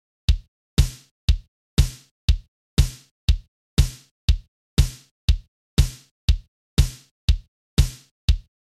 всем здравствуйте! не могли бы вы мне помочь? как добиться звучания снэйра и бочки как у c.c.catch? первый файл это не обработанный, второй обработанный если что там и там это те же семплы те же исходники